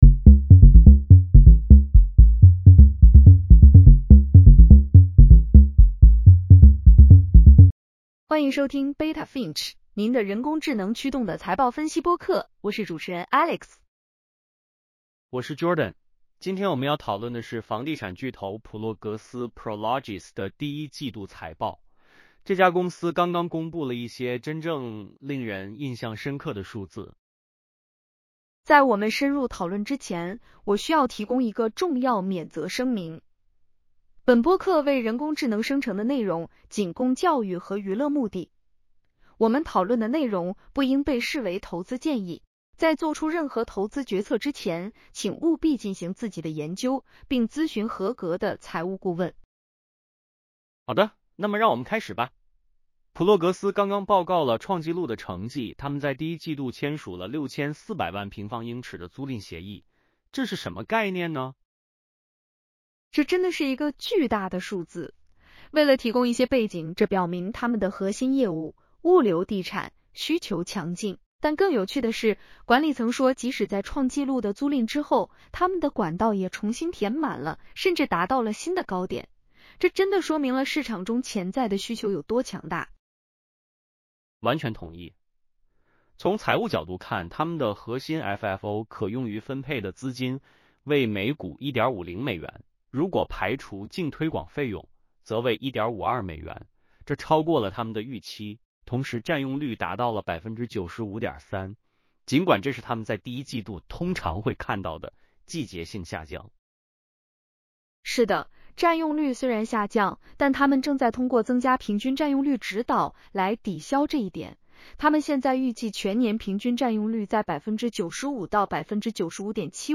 欢迎收听Beta Finch，您的人工智能驱动的财报分析播客。